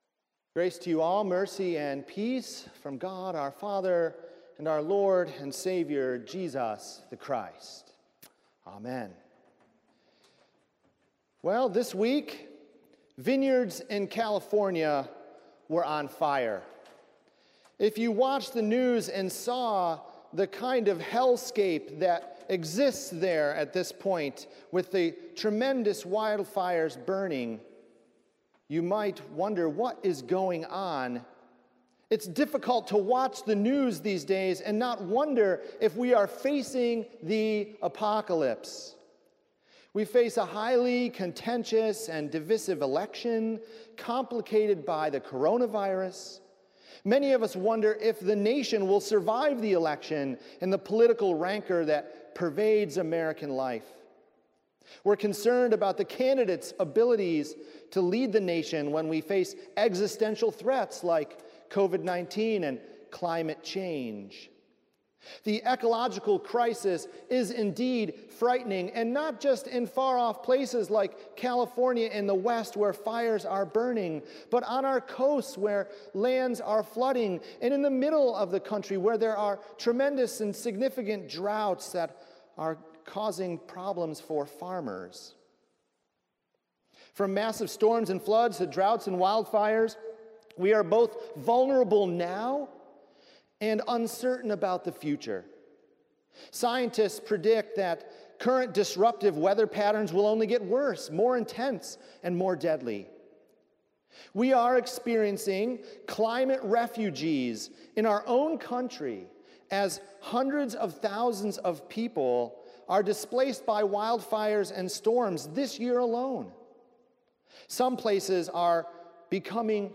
October 4 In this sermon